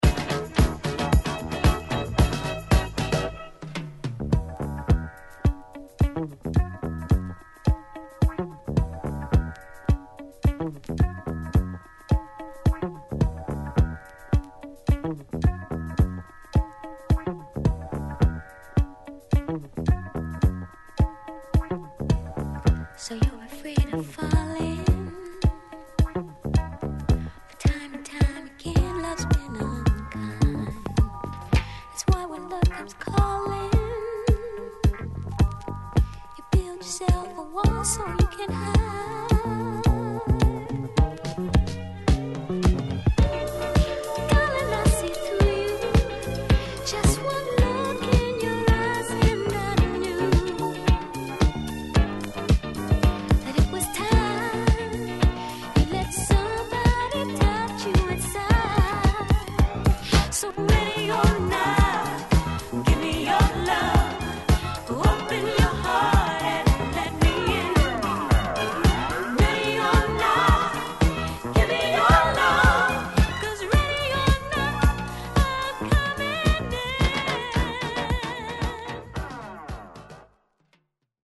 ・ SOUL / FUNK LP